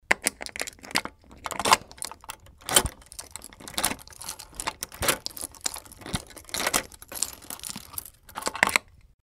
Ключ проворачивают в замочной скважине